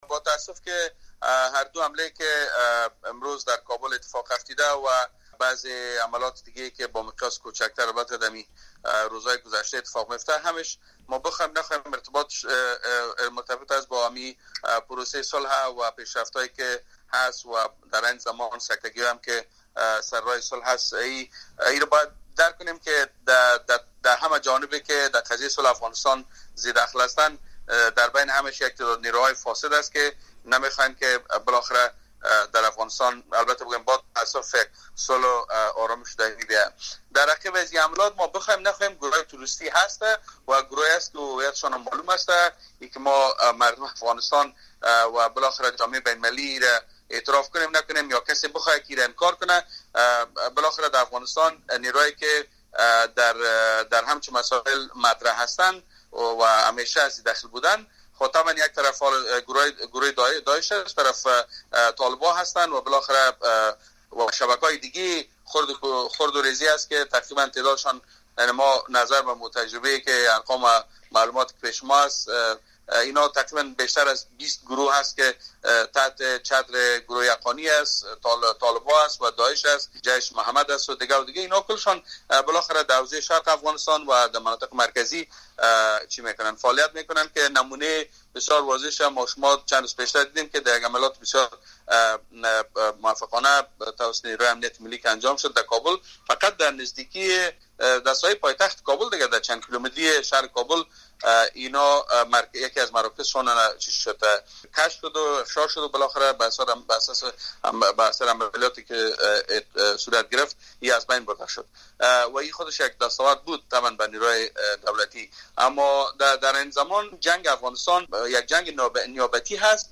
مصاحبه - صدا
ویس احمد برمک وزیر داخلۀ پیشین افغانستان